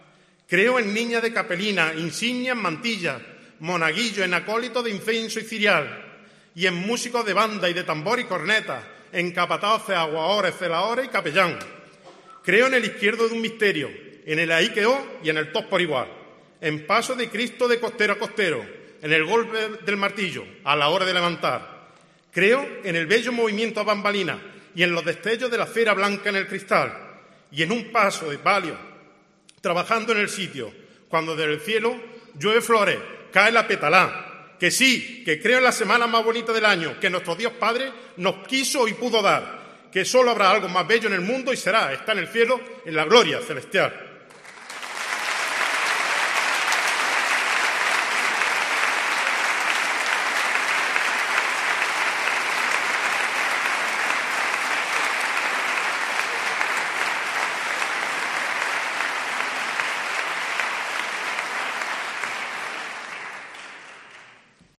Fragmento del pregón de la Semana Santa de Jaén de 2023